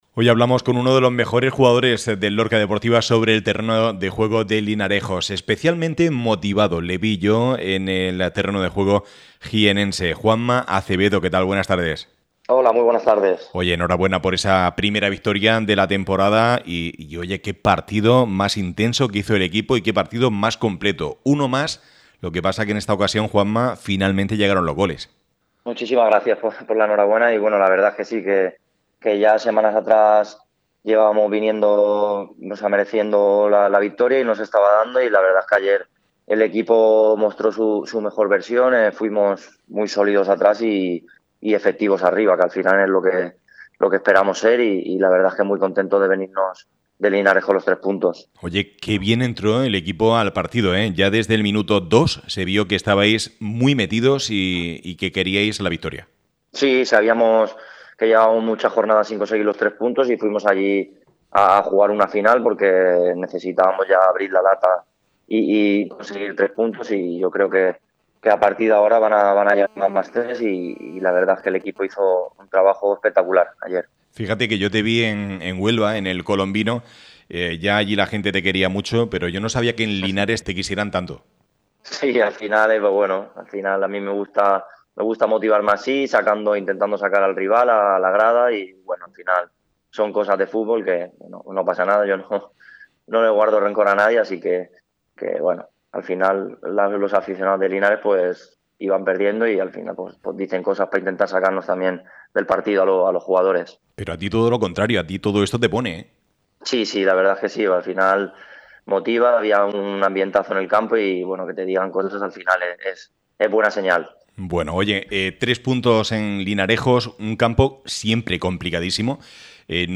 Escucha aquí su intervención este lunes en el programa deportivo de Área Lorca Radio